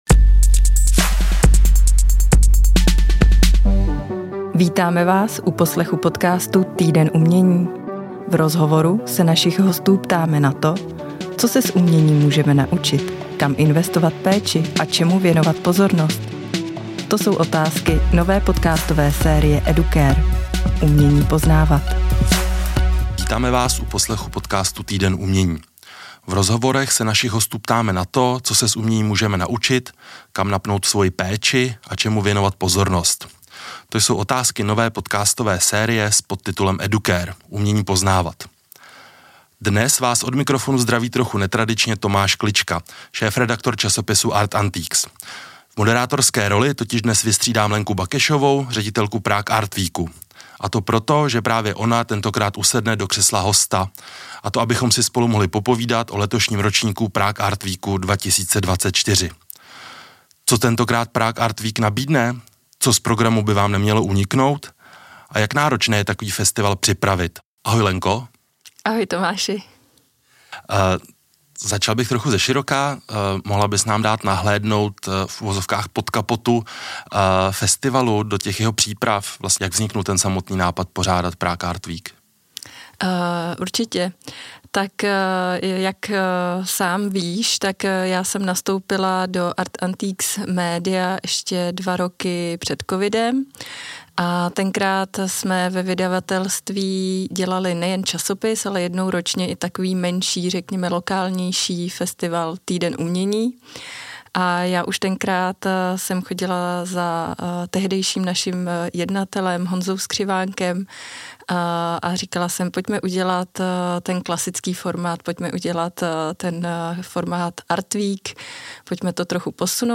V rámci rozhovoru přibližuje hlavní lokality, kde se třetí ročník festivalu odehraje (Palác Savarin, Centrum současného umění DOX, prostory v Jeruzalémské 2), a radí, jak na ten správný „art hunting“, tedy jakým způsobem se v bohaté programové nabídce nejsnáze zorientovat.